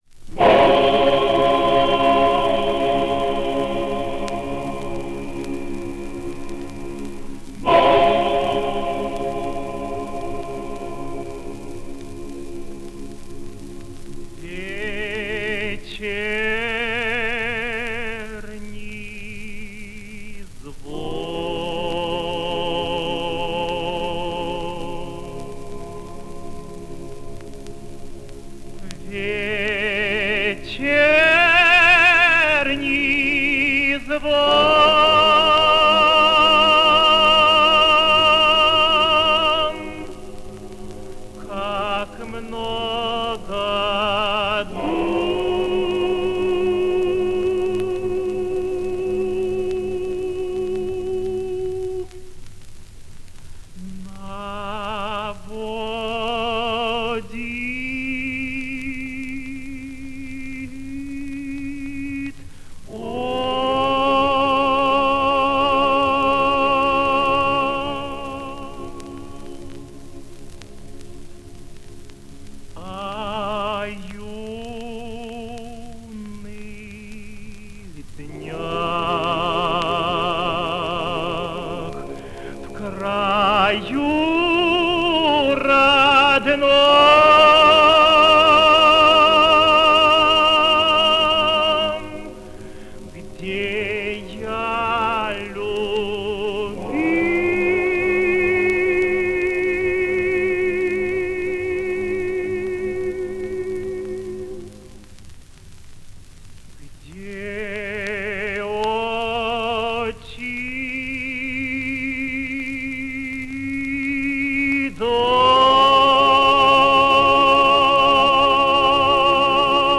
Исполняет И. С. Козловский.